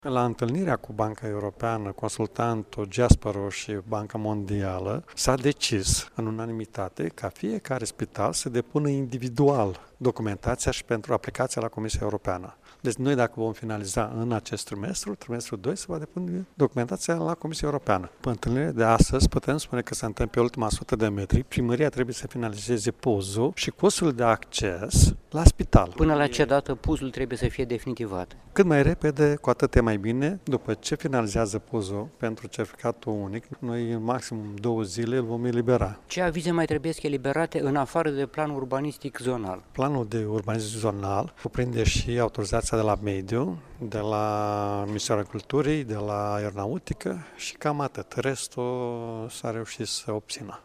Preşedintele Consiliului Judeţean Iaşi, Maricel Popa: